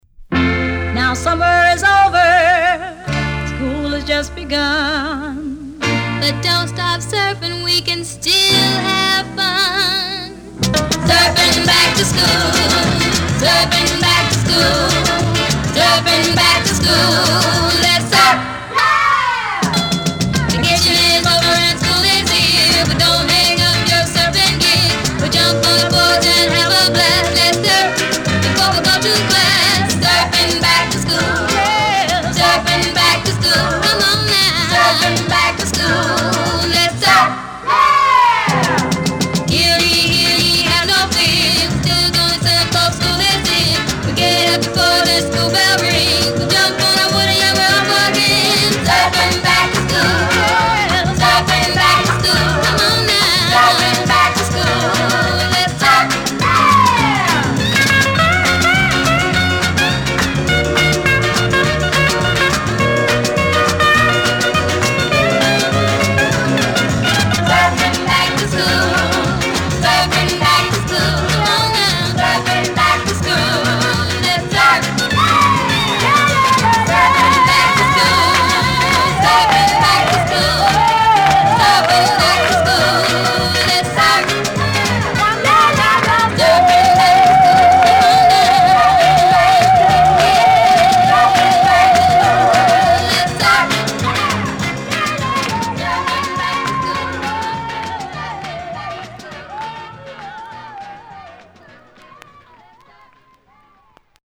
サーフをテーマとした軽快で爽やかなガールズ・グループ・チューンをビシッと決める。